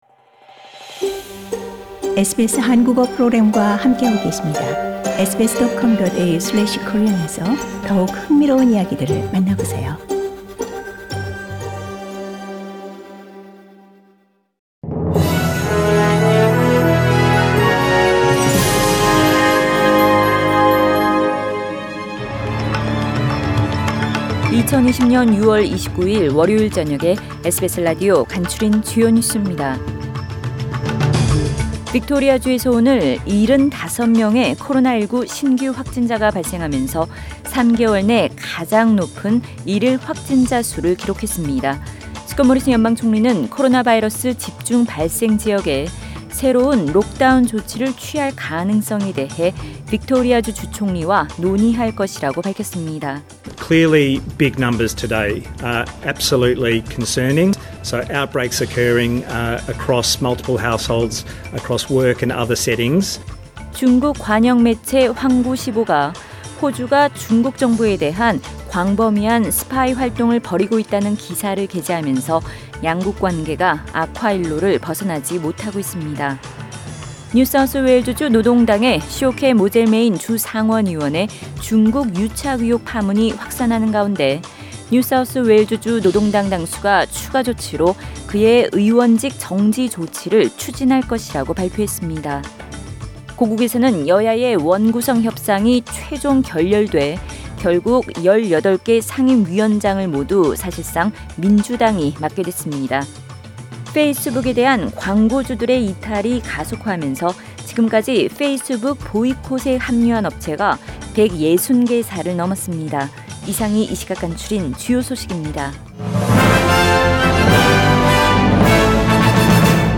2020년 6월 29일 월요일 저녁의 SBS Radio 한국어 뉴스 간추린 주요 소식을 팟 캐스트를 통해 접하시기 바랍니다.